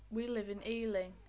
Examples of the most common tunes in the IViE corpus (read sentences)
Falling
Leeds female
falling-leeds-female.wav